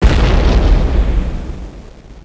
cannon.wav